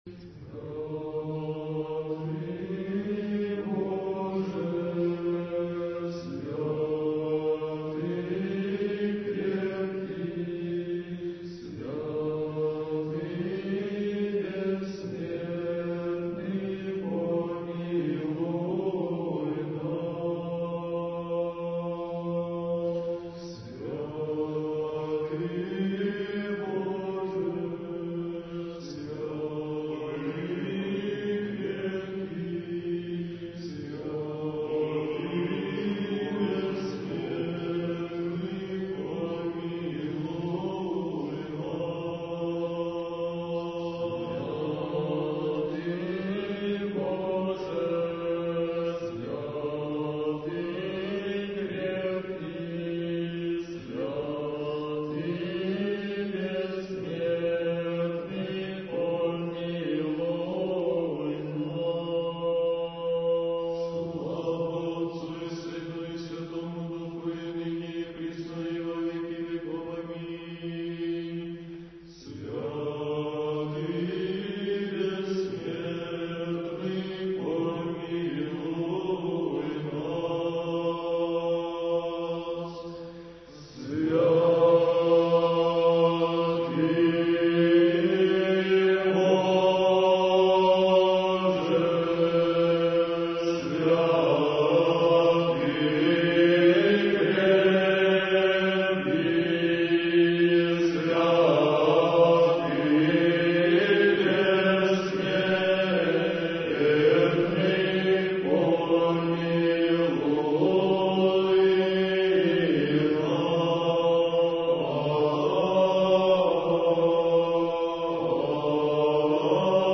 Духовная музыка / Русская